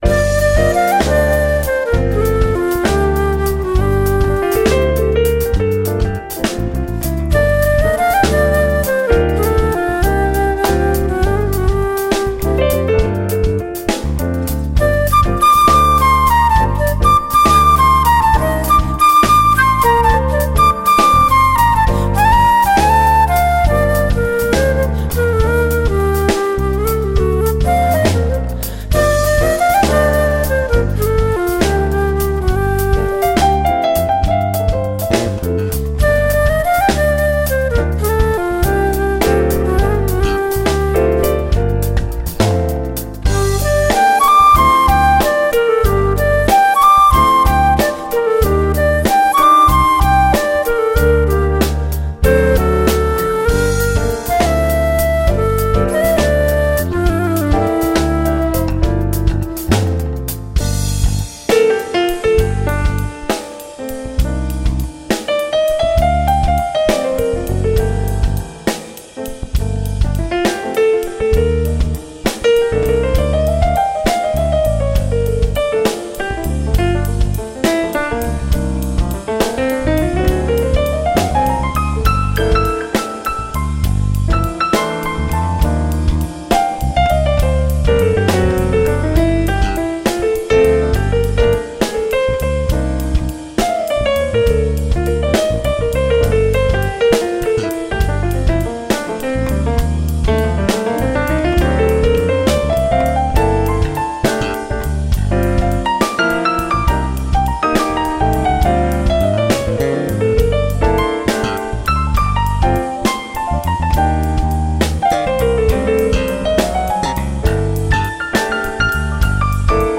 CD v súčasnom štýle groove-fusion-smooth-nu-...-jazz.
flauta, píšťalka, spev